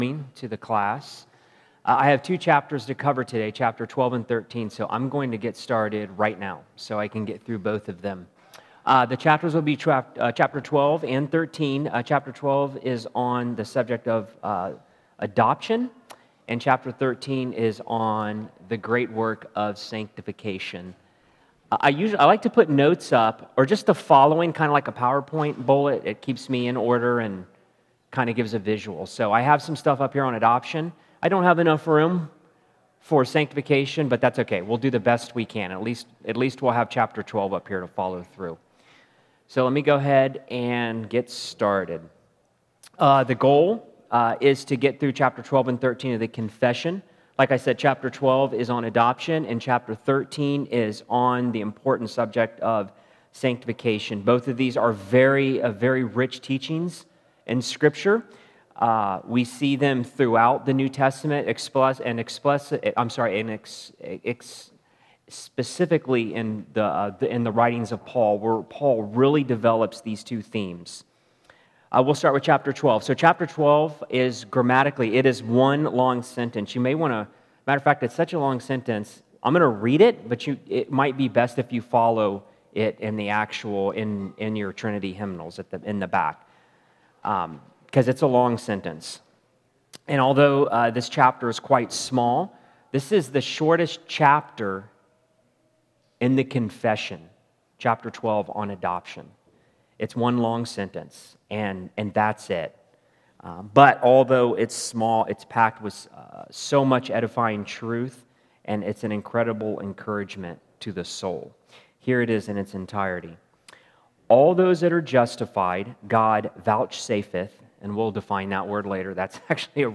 Westminster Confession Sunday School | New Life Presbyterian Church of La Mesa